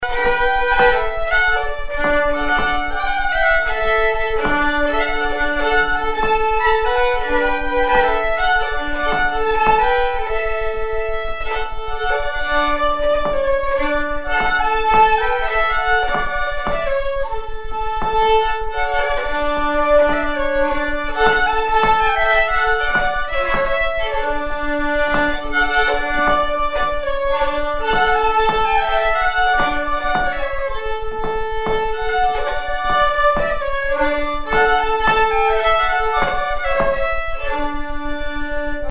(slow)